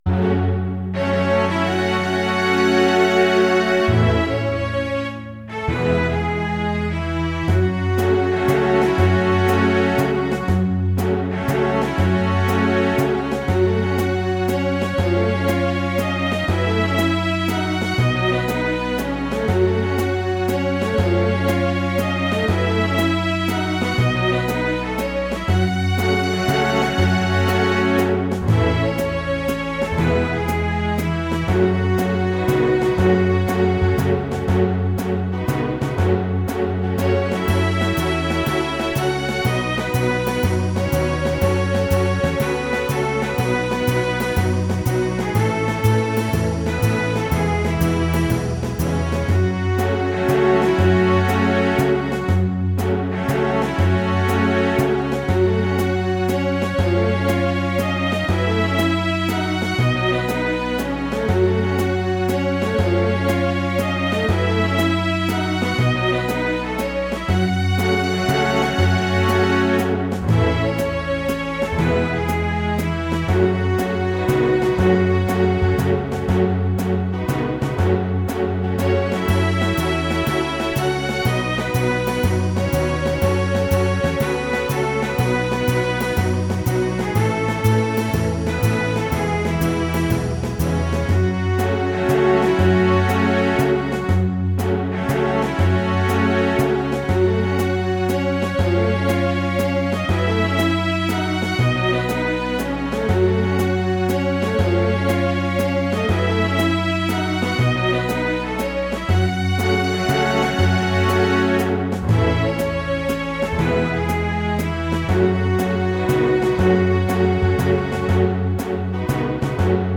Cello Double Bass Tympani
Drums Percussion Tuned Percussion
Piano Harpsichord Hammond Organ
Synthesizer Classical Guitar Electric Guitar